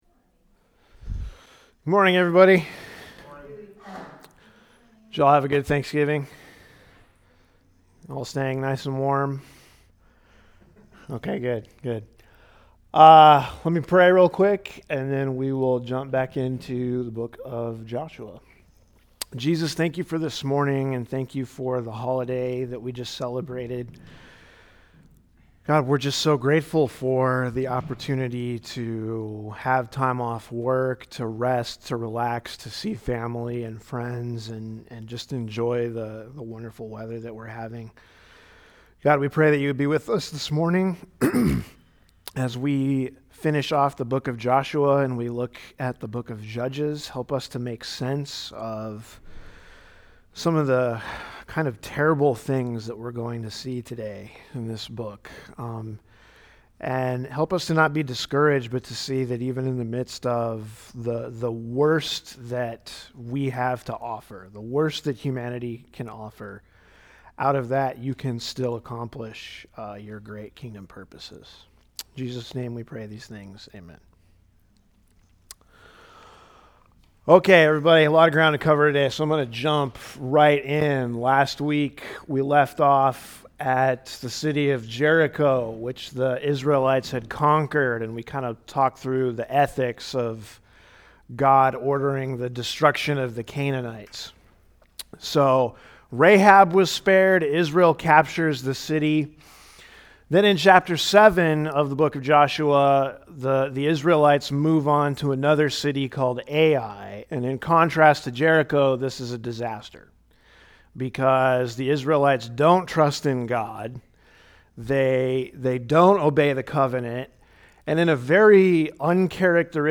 A message from the series "Old Testament Survey."